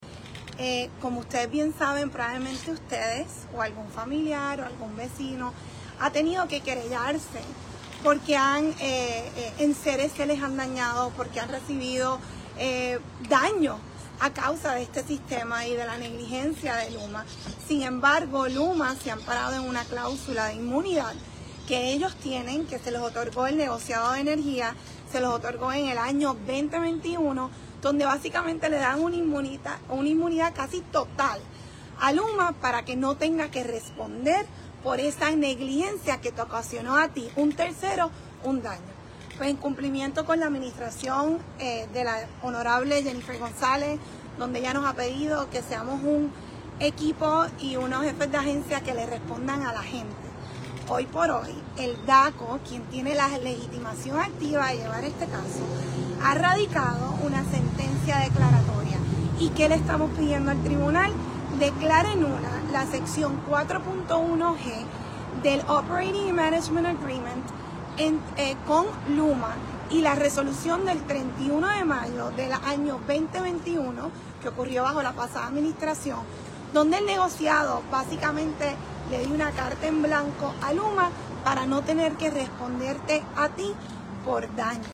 “LUMA se ha amparado en una clausula de imunidad que ellos tienen, que se los otorgó el Negociado de Energía, se los otorgó en el año 2021 donde básicamente le dan una inmunidad casi total a LUMA para que no tenga que responder por esa negligencia que te ocasionó a ti”, dijo la secretaria de DACO, Valerie Rodríguez frente al Tribunal de San Juan.